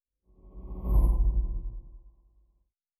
pgs/Assets/Audio/Sci-Fi Sounds/Movement/Fly By 06_2.wav at master
Fly By 06_2.wav